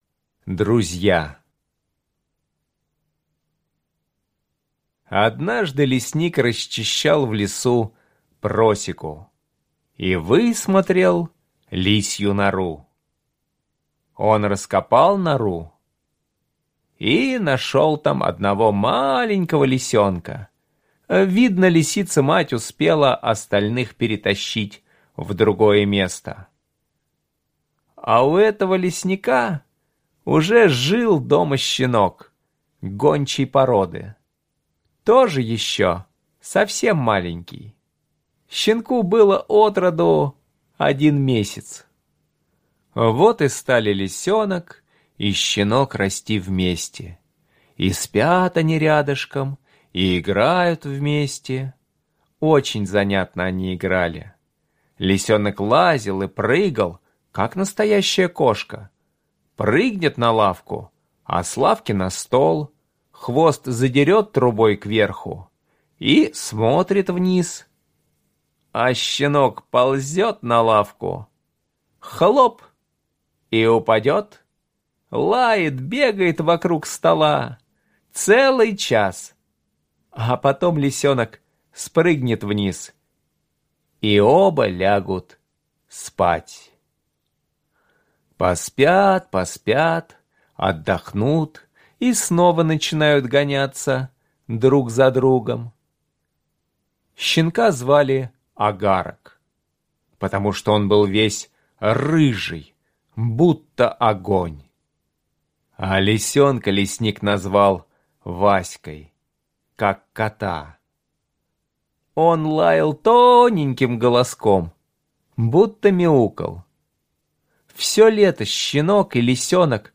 Аудиорассказ «Друзья»